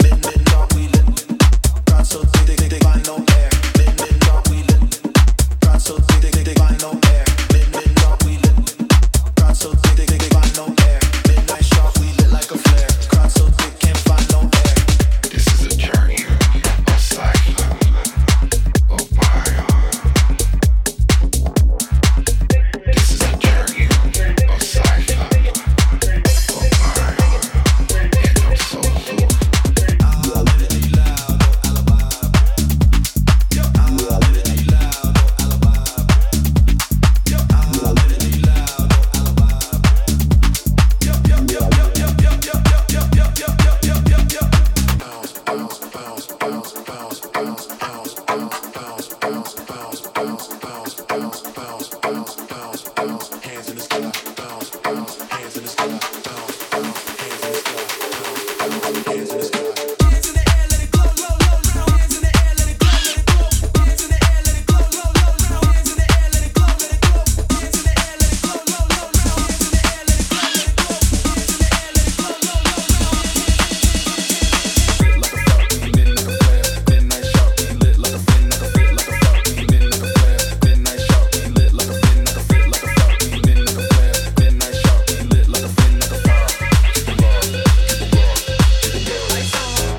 Genre:Tech House
デモサウンドはコチラ↓